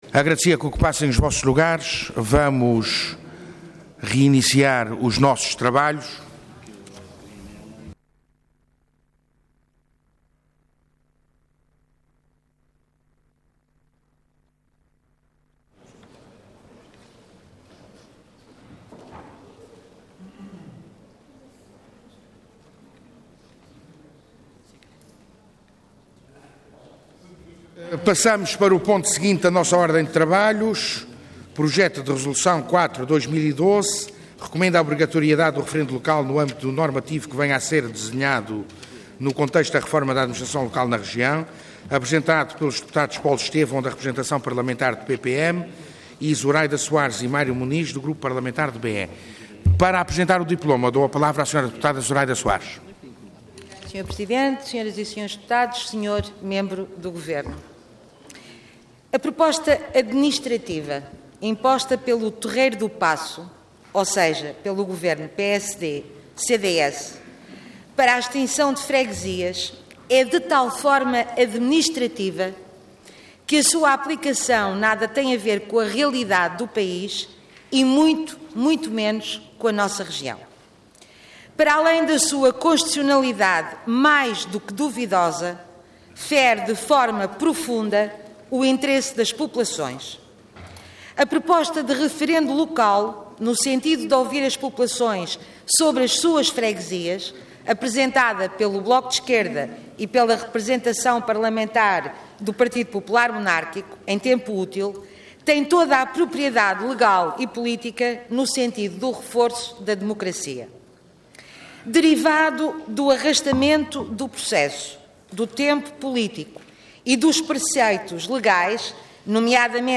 Intervenção Projeto de Resolução Orador Zuraida Soares Cargo Deputada Entidade Autores Vários